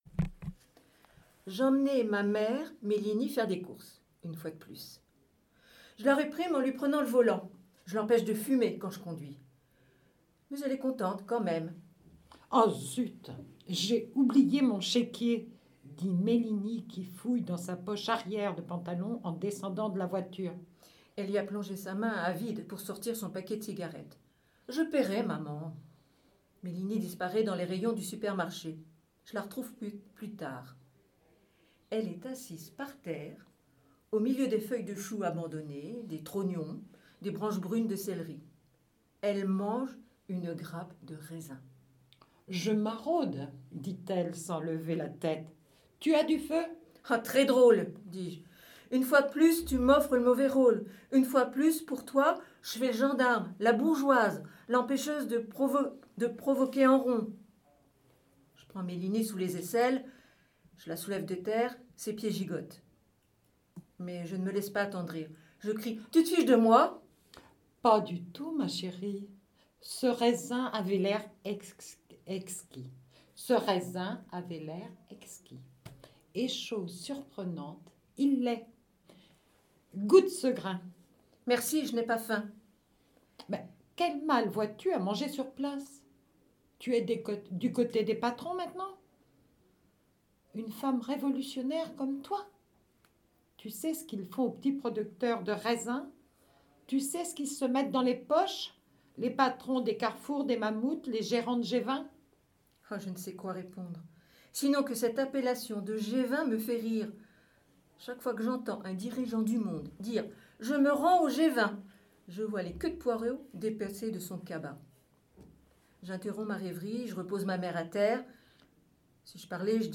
Les Haut Parleurs de l'association "Lire à Saint-Lô" prêtent leurs voix sur MDR dans l’ émission "Lire et délire" ! Dans cette émission, nous écoutons des extraits des livres suivants : « Mme Pylinska et le secret de chopin » d’Eric Schmitt « Sa majesté ver-de-terre» de Piret Raud « Je haïs les femmes » de Dorothy Parker «Le petit prince » de Antoine de Saint Exupéry, "Chagrin d'aimer" de Genevieve Brisac, " Les philos fables pour la terre " de Michel Piquemal, "Courts et légendes de l'oiseau" de Michel Bournaud, " Les pas perdus " de Denise Bonal et "Une odyssée" de Daniel Mendelson.
Ces lectures sont dispersées dans les émissions 9.10 et 11